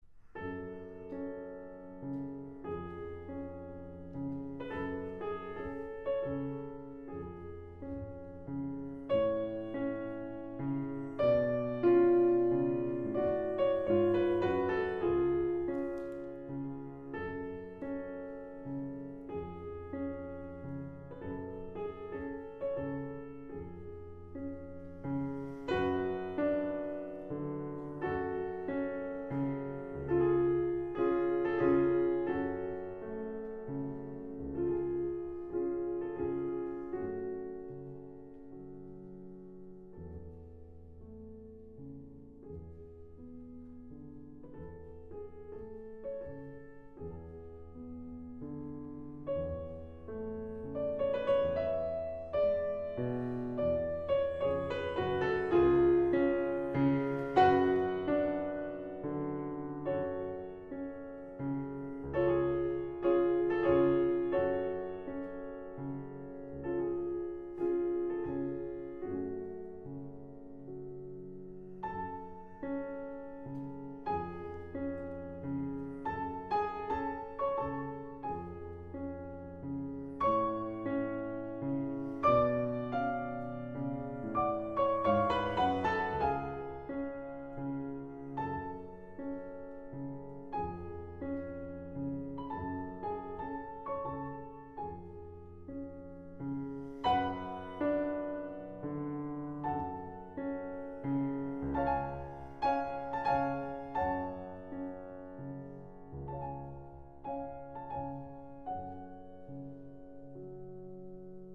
wgbh-music-paul-lewis-plays-schuberts-piano-sonata-no-20-in-a-major-andantino-audiotrimmer-com.mp3